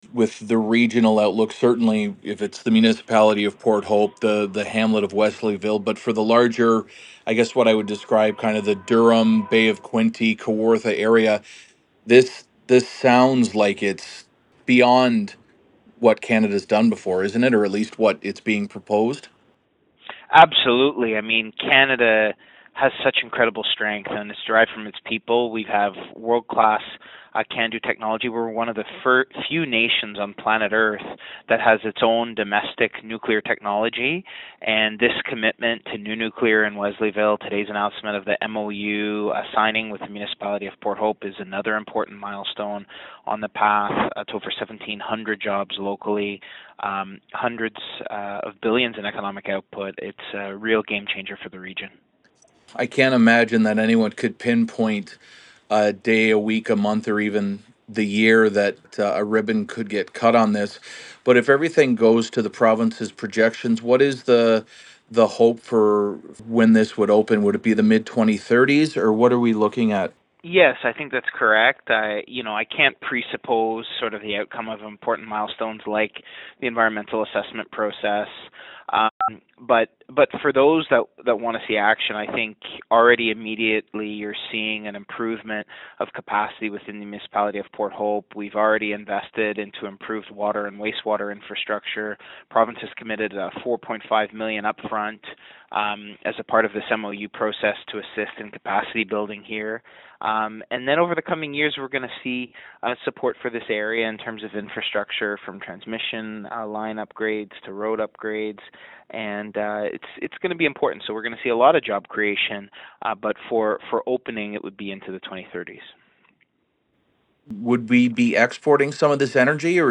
A longer conversation with MPP David Piccini